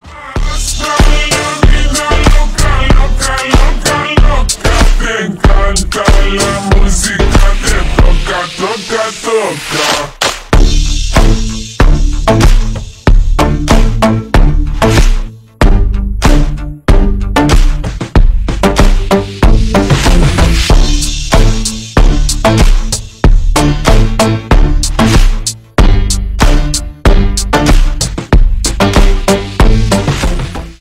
Ремикс
латинские # громкие # клубные